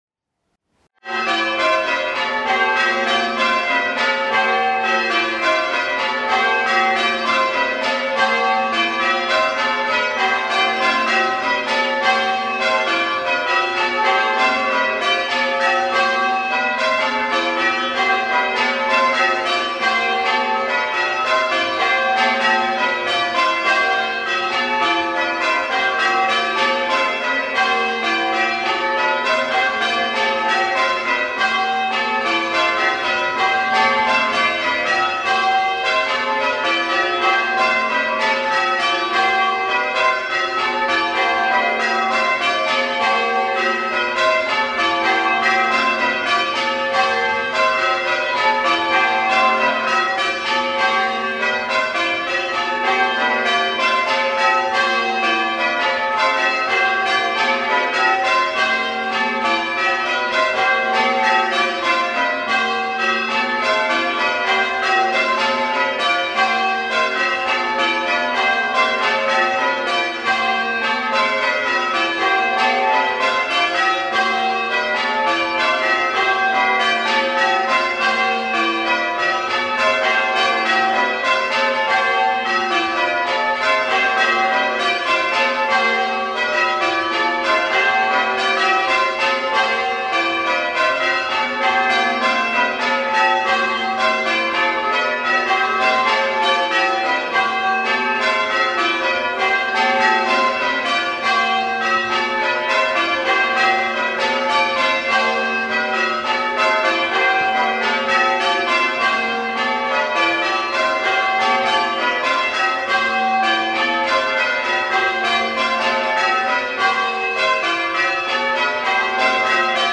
Plain Bob Doubles , rung by the Ipswich Minster ‘Blue team’ on their way to winning the Cecil Pipe Memorial Bell Trophy for method ringing, at the District Striking Competition on 7 th May 2025